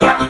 robo_bo_fire_vo_01.ogg